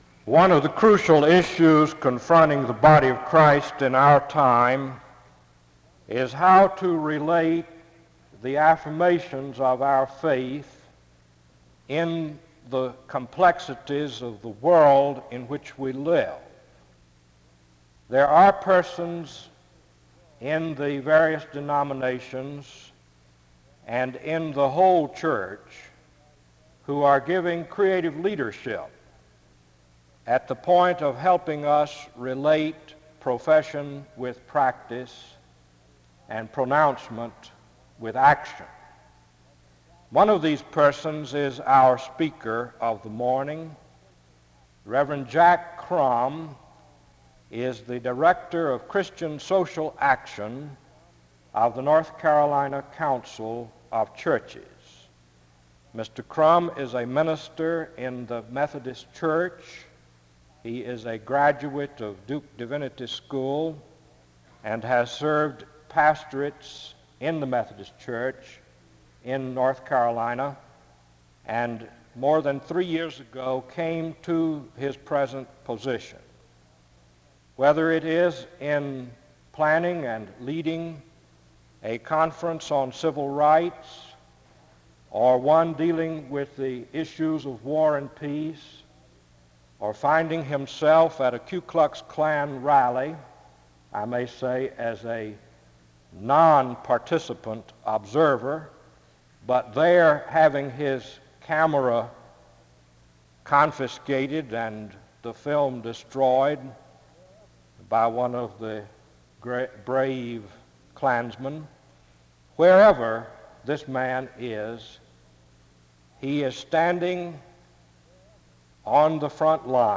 The service opens with an introduction to the speaker from 0:00-2:21.